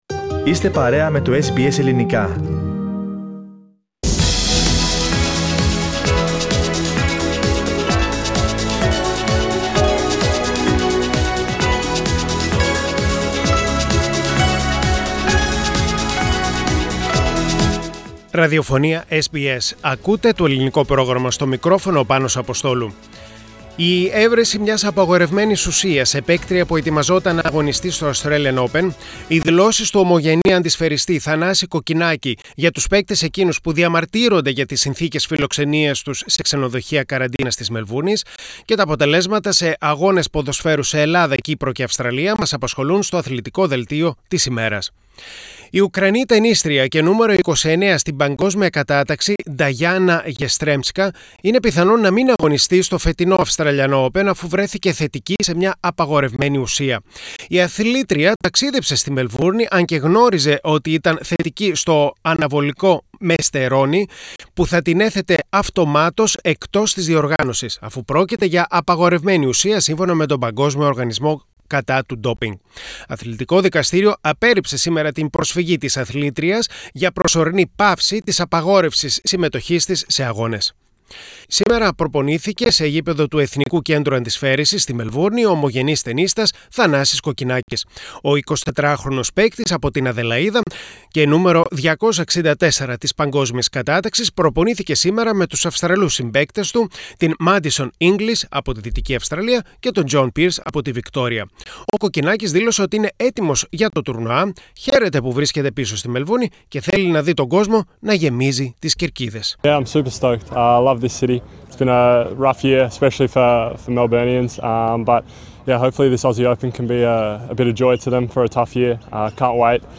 Ο εντοπισμός απαγορευμένης ουσίας σε παίκτρια που ετοιμαζόταν να αγωνιστεί στο Australian Open, οι δηλώσεις του ομογενή τενίστα Θανάση Κοκκινάκη για τους παίκτες εκείνους που διαμαρτύρονται για την συνθήκες φιλοξενία τους σε ξενοδοχεία καραντίνας και τα αποτελέσματα σε αγώνες ποδοσφαίρου σε Ελλάδα, Κύπρο και Αυστραλία στο Αθλητικό Δελτίο της ημέρας (Kυριακή 24.01.21)